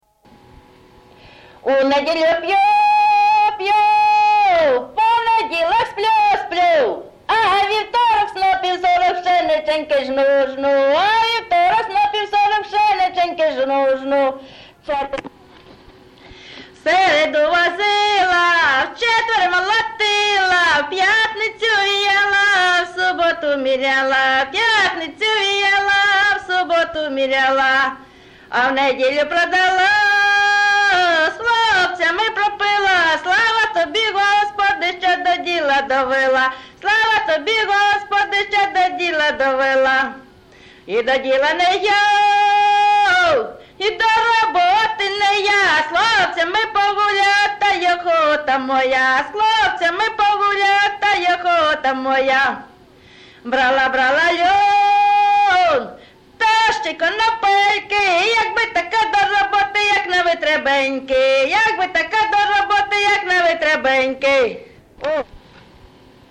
ЖанрЖартівливі, Пʼяницькі
Місце записус. Яблунівка, Костянтинівський (Краматорський) район, Донецька обл., Україна, Слобожанщина